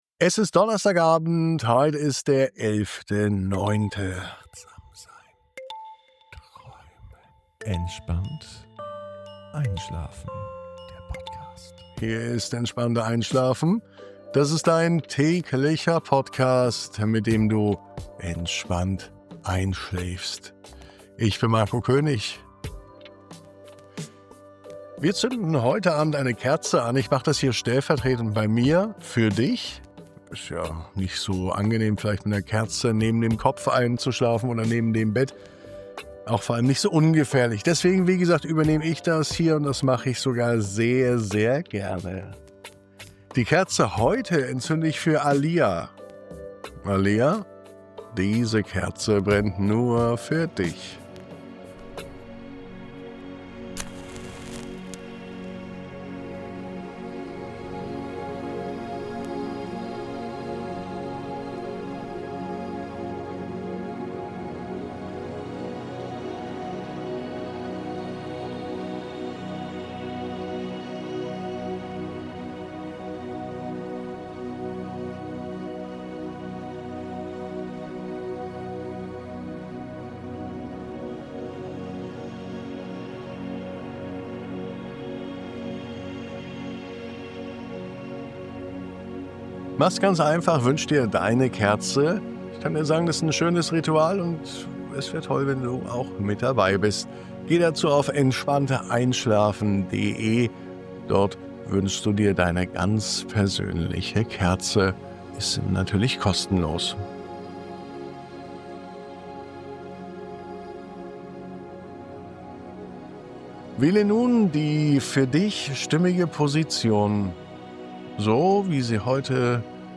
Diese Traumreise begleitet dich durch einen leuchtenden Wald voller Farben, leiser Geräusche und wohltuender Ruhe. Spüre, wie jeder Schritt auf dem weichen Laubboden dich näher zu dir selbst bringt – bis dein Körper loslässt und der Schlaf dich sanft umhüllt.